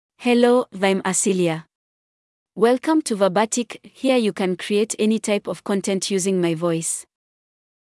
FemaleEnglish (Kenya)
Asilia — Female English AI voice
Asilia is a female AI voice for English (Kenya).
Voice sample
Asilia delivers clear pronunciation with authentic Kenya English intonation, making your content sound professionally produced.